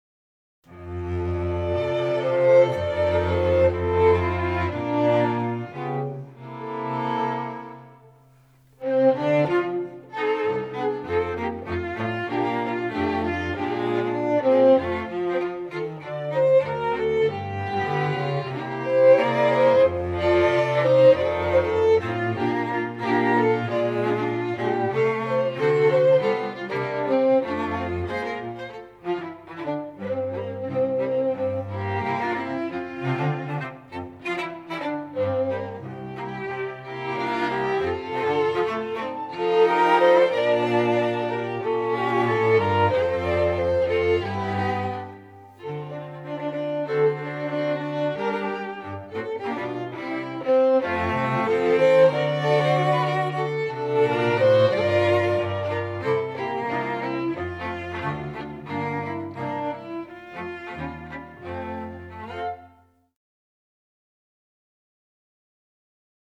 Violine I
Violine II
Viola
Violoncello